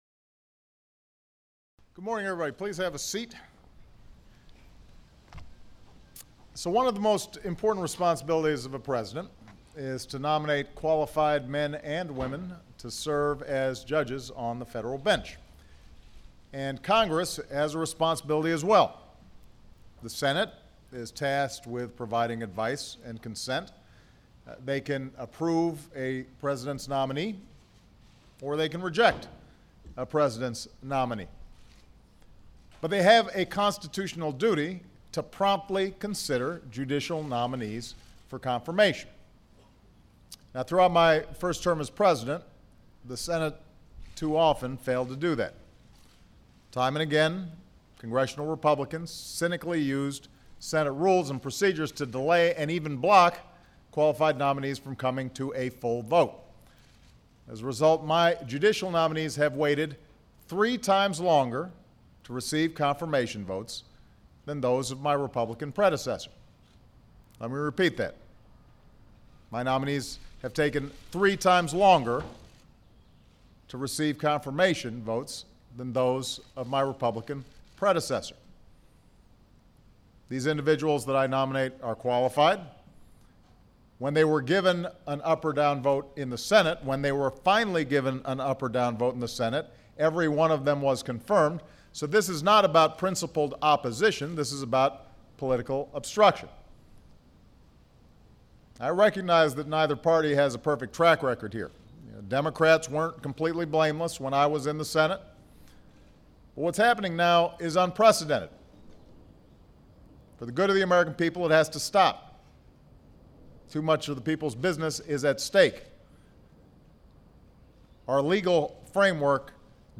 U.S. President Barack Obama announces his nomination of three candidates for the United States Court of Appeals for the District of Columbia Circuit